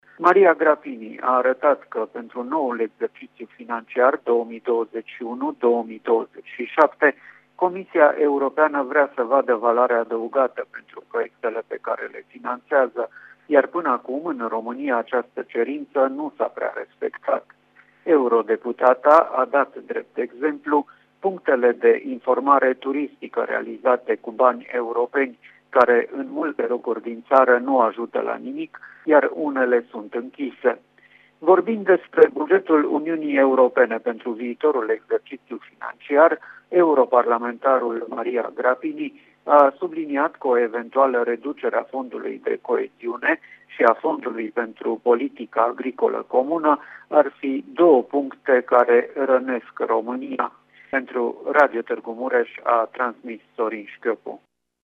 Aceasta este părerea europarlamentarului Maria Grapini, care a participat astăzi la sibiu la o dezbatere găzduită de Universitatea Lucian Blaga cu privire la bugetul Uniunii Europene după 2020.